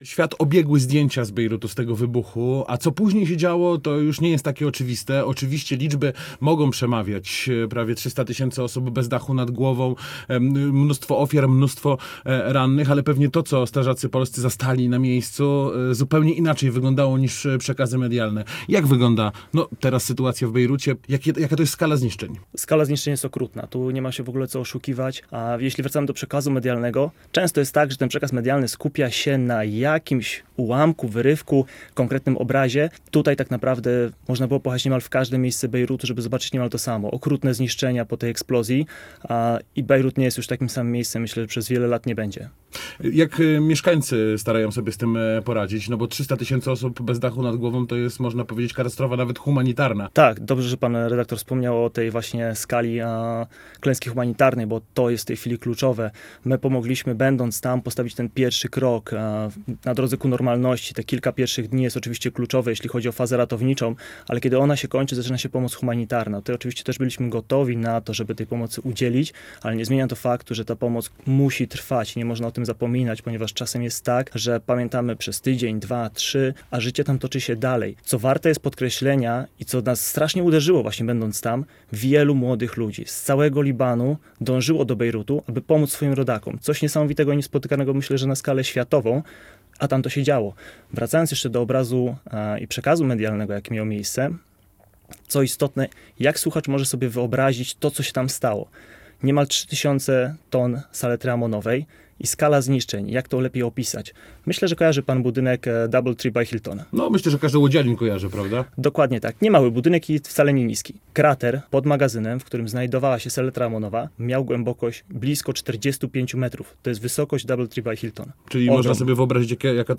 Rozmowa z łódzkim strażakiem, który brał udział w akcji ratowniczej w Bejrucie [WIDEO] - Radio Łódź